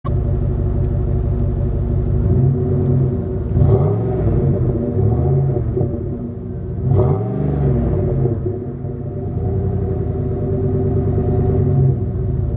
kurzer Soundcheck :mgr: